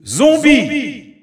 The announcer saying Zombie's name in French.
Category:Steve (SSBU) Category:Announcer calls (SSBU) You cannot overwrite this file.
Zombie_French_Announcer_SSBU.wav